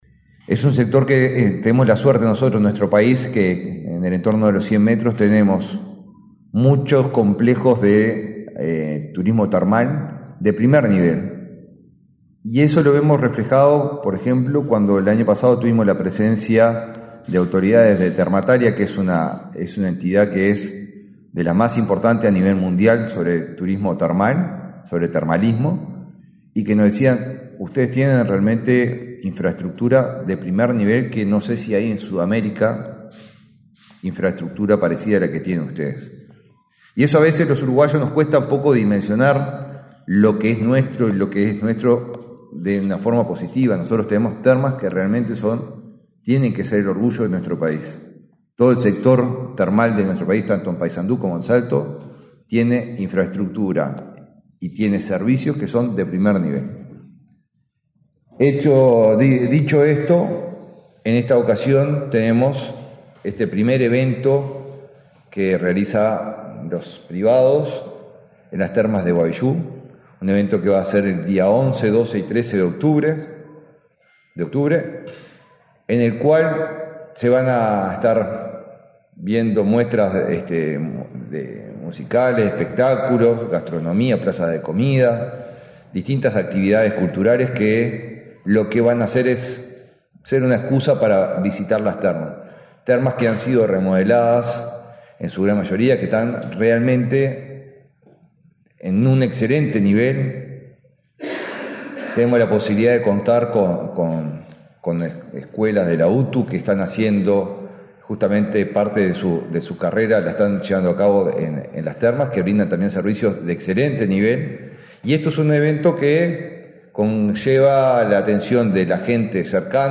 Palabras del ministro de Turismo, Eduardo Sanguinetti
Este lunes 7 en Montevideo, el ministro de Turismo, Eduardo Sanguinetti, participó, del acto de lanzamiento de la celebración del 67.° aniversario de